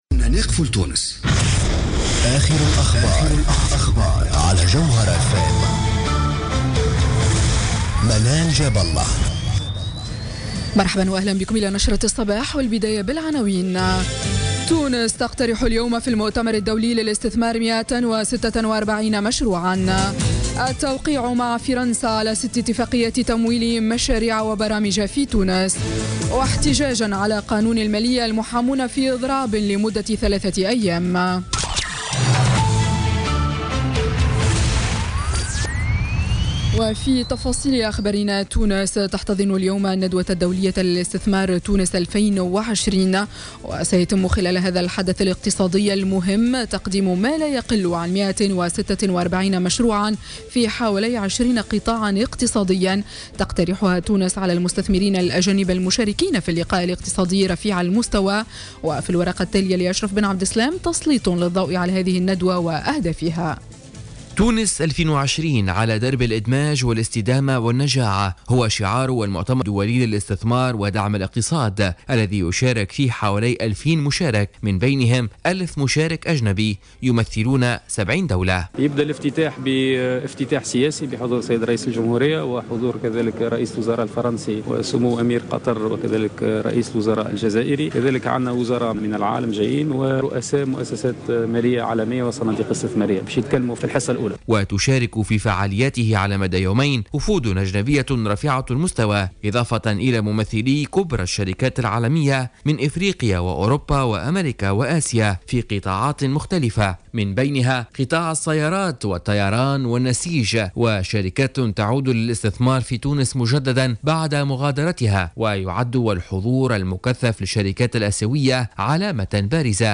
نشرة أخبار السابعة صباحا ليوم الثلاثاء 29 نوفمبر 2016